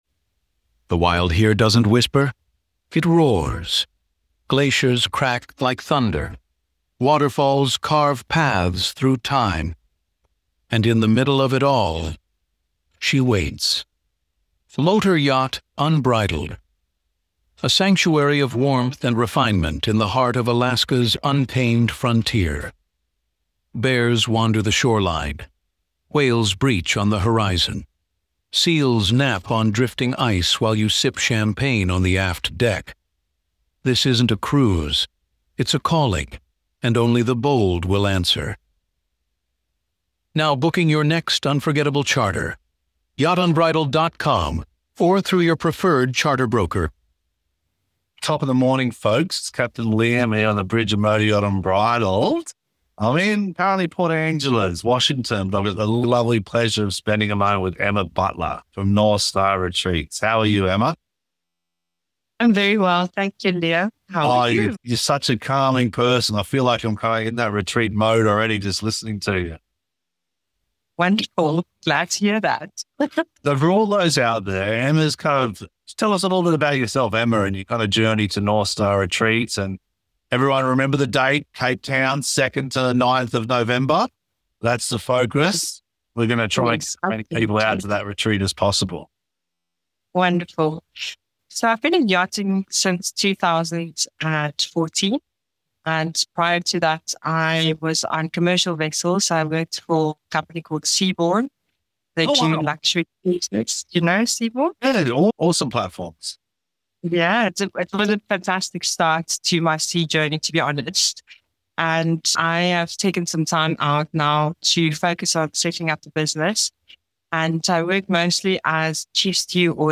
Captain’s Chat